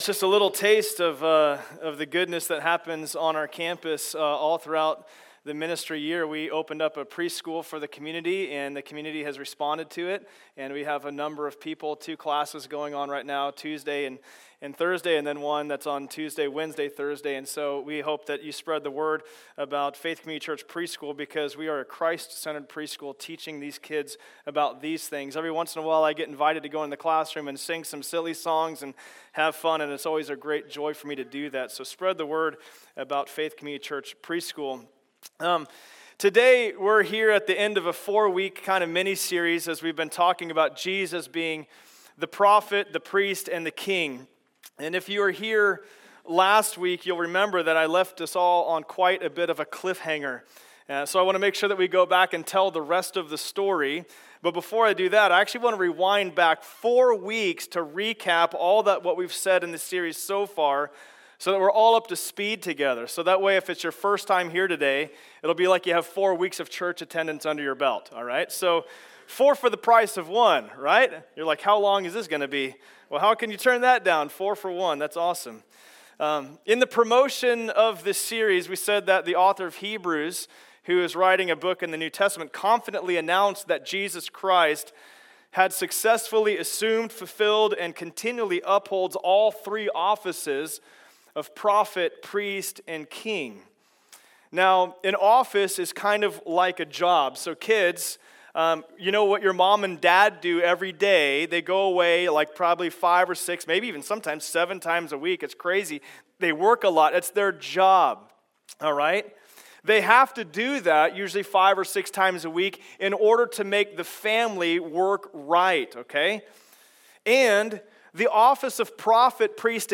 King Service Type: Sunday Service Download Files Notes « Jesus